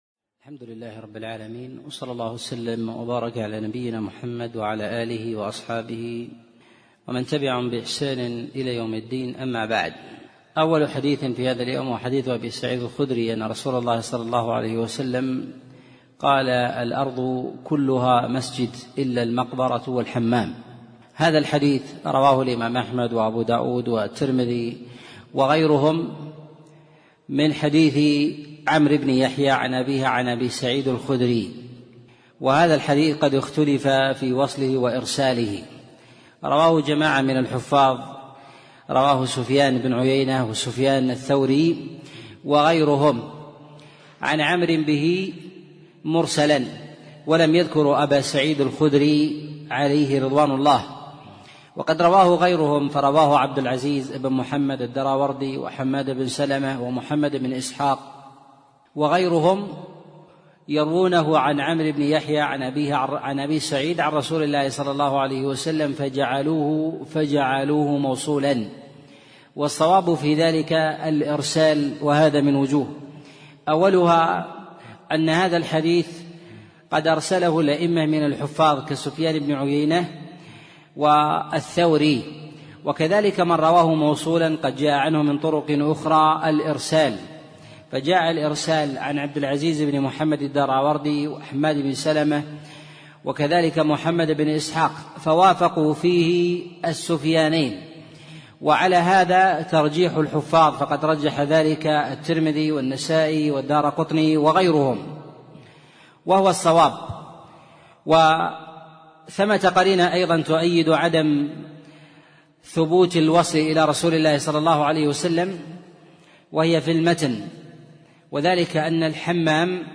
أرشيف الإسلام - ~ أرشيف صوتي لدروس وخطب ومحاضرات الشيخ عبد العزيز الطريفي